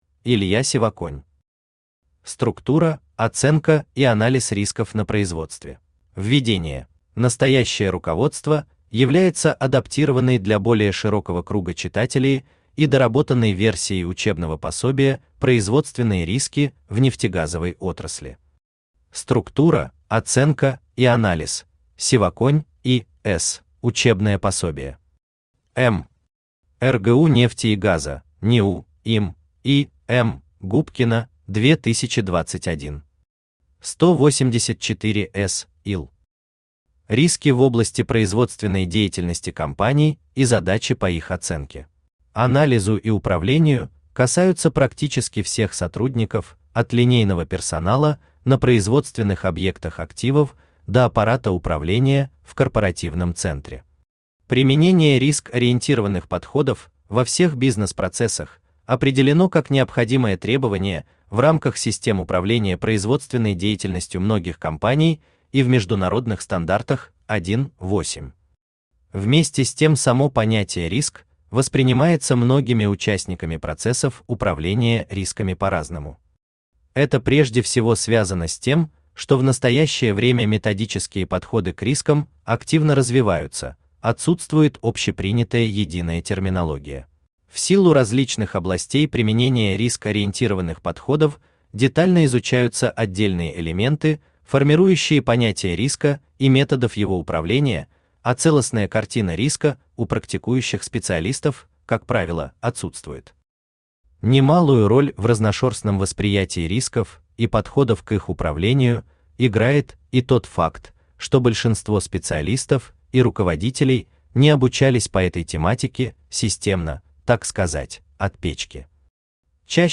Аудиокнига Структура, оценка и анализ рисков на производстве | Библиотека аудиокниг
Aудиокнига Структура, оценка и анализ рисков на производстве Автор Илья Сергеевич Сивоконь Читает аудиокнигу Авточтец ЛитРес.